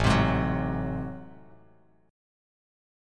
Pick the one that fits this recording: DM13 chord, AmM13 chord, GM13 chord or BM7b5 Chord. AmM13 chord